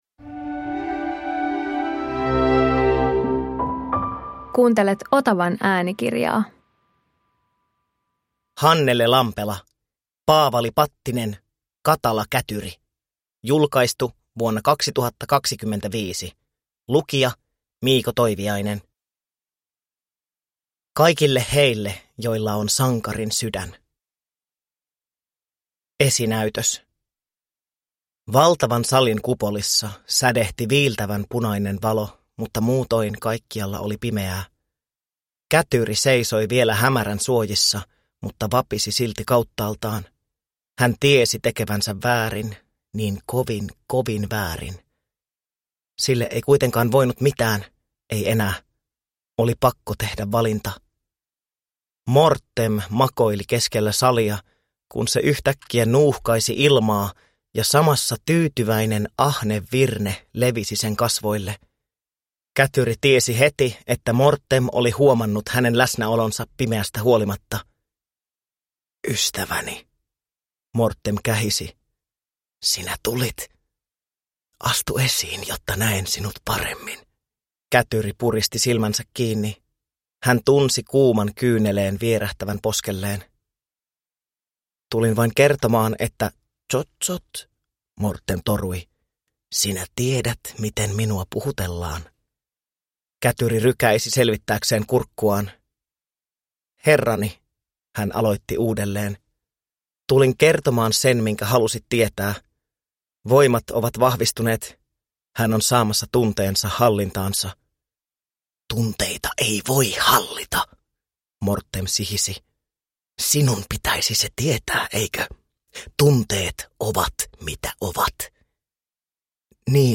Paavali Pattinen – Katala kätyri – Ljudbok